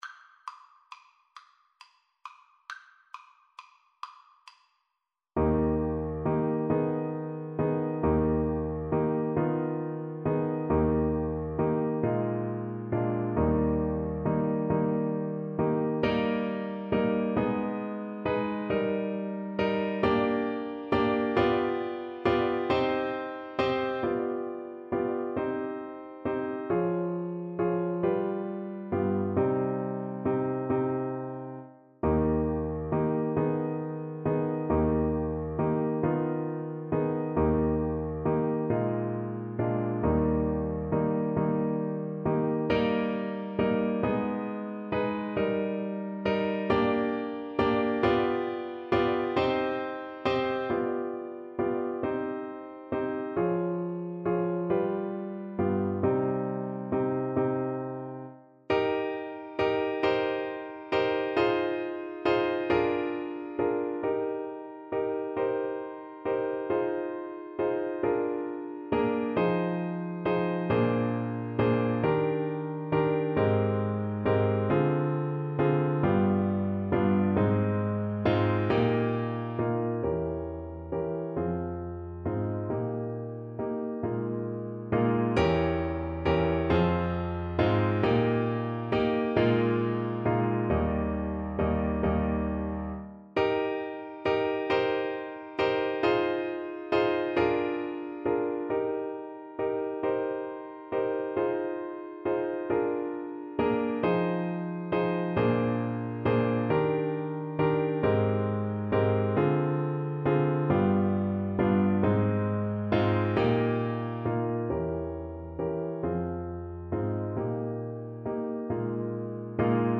Eb major (Sounding Pitch) (View more Eb major Music for Violin )
Andantino .=c.45 (View more music marked Andantino)
6/8 (View more 6/8 Music)
Classical (View more Classical Violin Music)